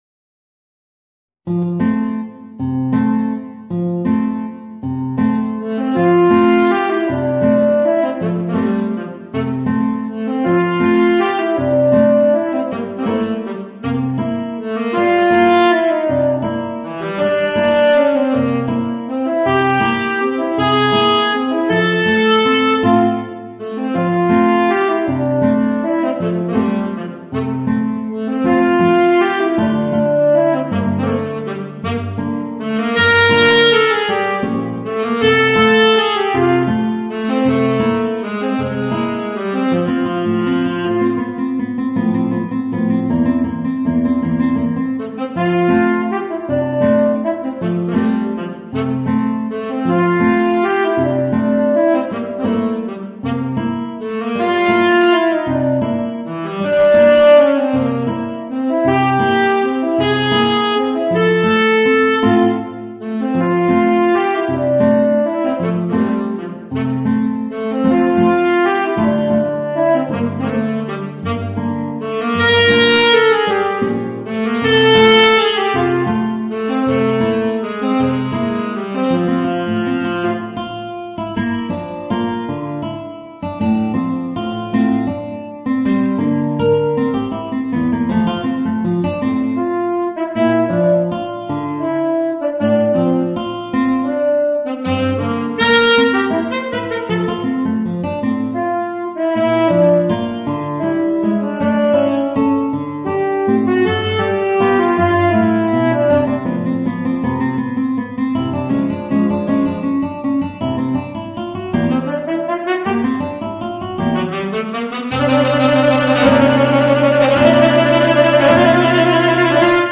Voicing: Guitar and Tenor Saxophone